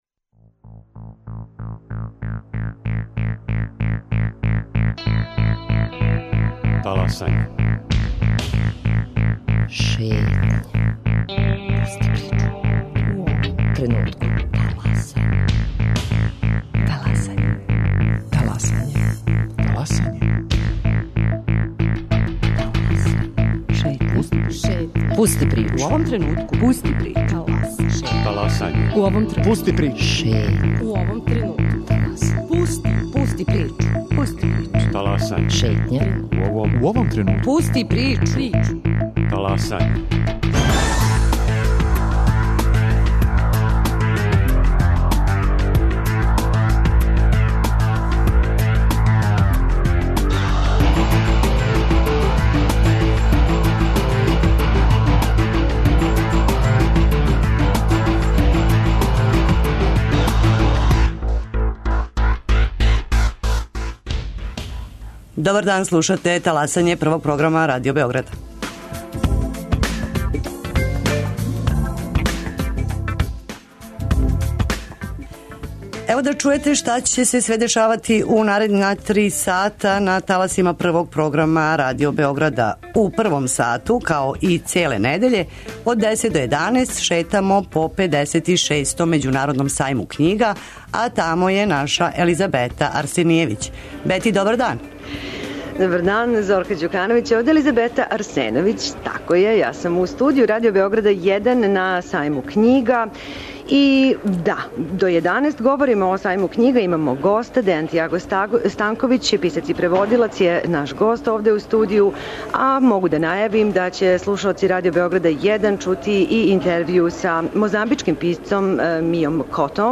Ове недеље шетамо 56. Међународним Сајмом књига у Београду – наш студио биће на сајму, разговараћемо са издавачима, писцима, посетиоцима... Почасни гост ове године су земље португалског говорног подручја - Ангола, Бразил, Гвинеја Бисао, Зеленортска острва, Источни Тимор, Мозамбик, Португал и Сао Томе.